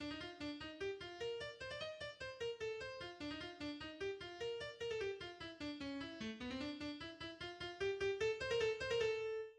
en si bémol majeur
Genre Symphonie
Le Presto est de forme rondo-sonate.
Première reprise du Presto :